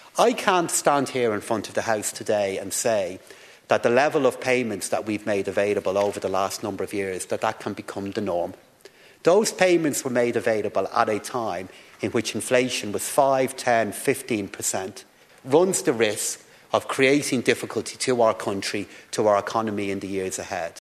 Finance Minister Paschal Donohoe, says the country needs to be weaned away from such payments……………